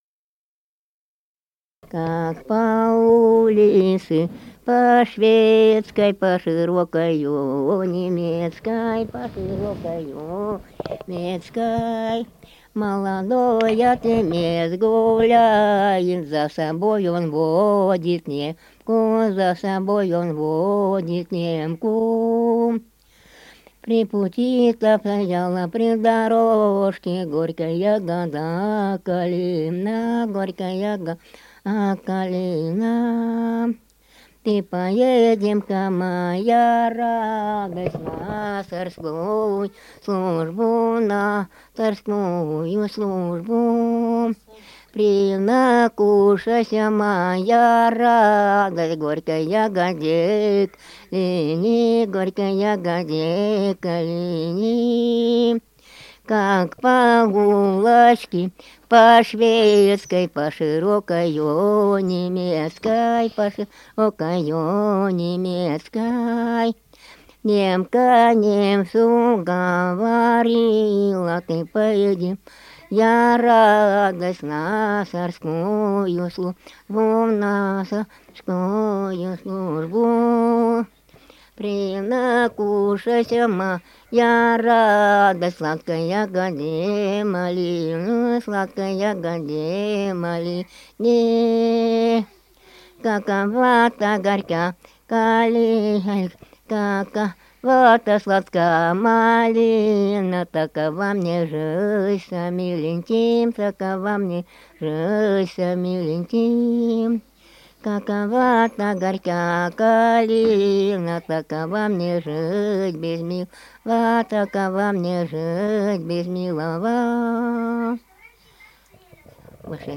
Русские песни Алтайского Беловодья 2 [[Описание файла::«Как по улице по шведской», круговая.
Республика Алтай, Усть-Коксинский район, с. Верхний Уймон, июнь 1980.